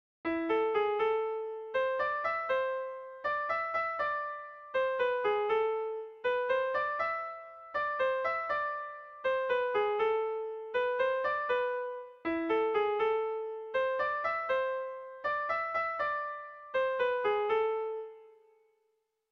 Irrizkoa
Baxenabarre < Euskal Herria
Lau puntuko berdina, 8 silabaz
ABDE